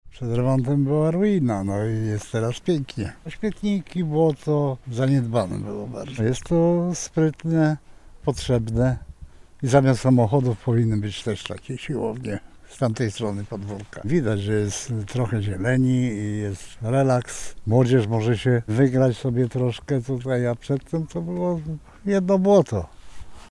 Teraz jest lepiej – mówi mieszkaniec kamienicy przy u. Mierniczej.
01_sonda-Miernicza-podworko.mp3